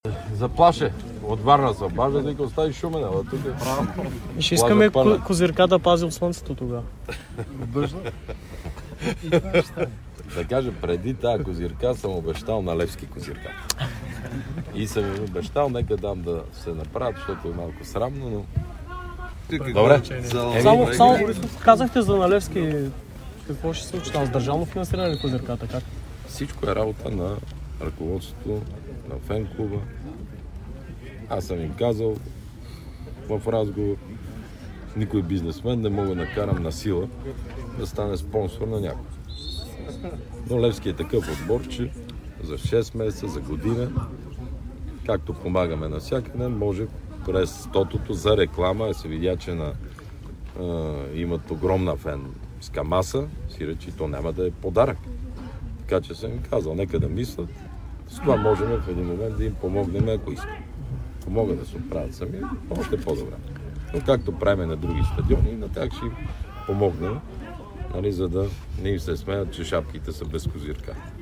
Премиерът на България Бойко Борисов коментира ситуацията в Левски по време на инспекция си на стадион "Панайот Волов" в Шумен, за чиято реконструкция държавата отпусна 3 млн. лева.